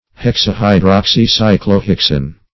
hexahydroxycyclohexane - definition of hexahydroxycyclohexane - synonyms, pronunciation, spelling from Free Dictionary
hexahydroxycyclohexane.mp3